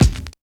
88 KICK 4.wav